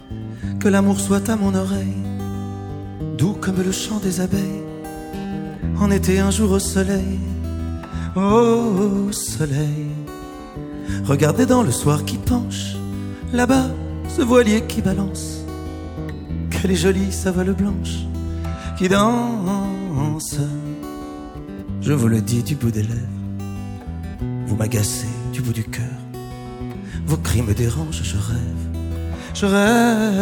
"templateExpression" => "Chanson francophone"